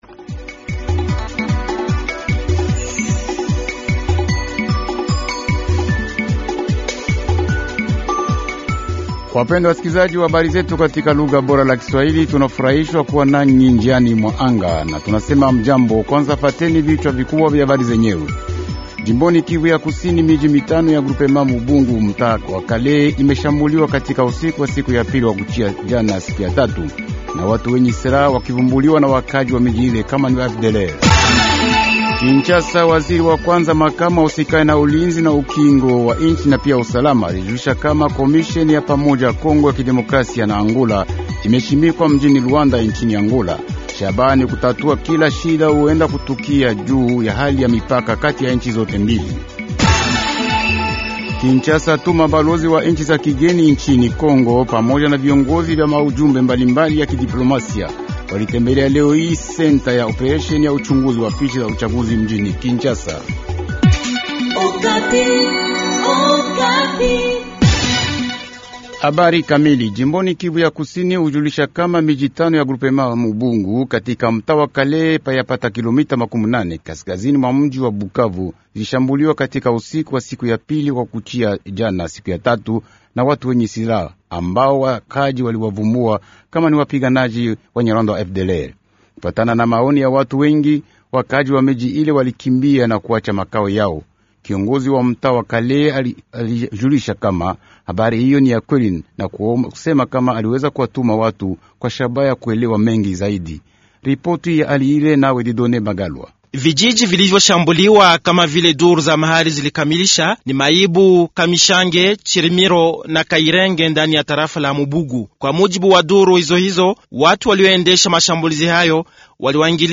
Journal Swahili Soir